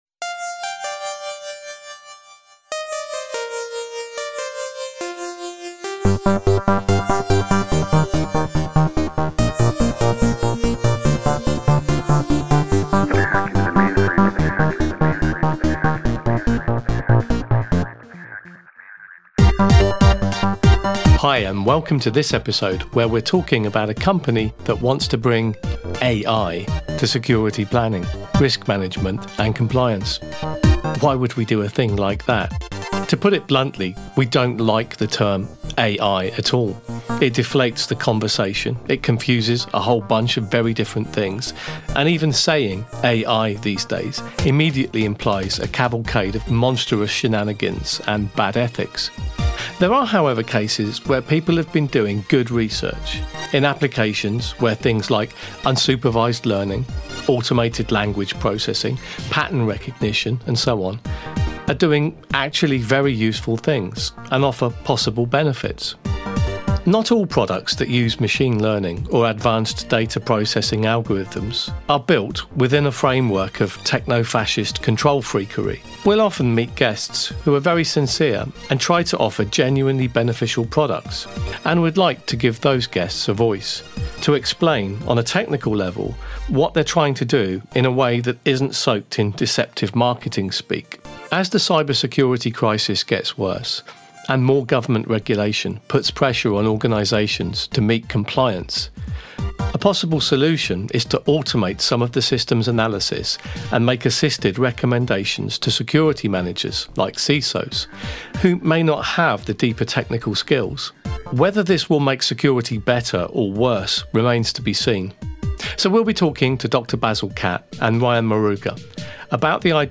Categories: Audio Only In The Chair Interview Legislation and regulation Safety Cyber Security Practice Machine Learning Language Model Applications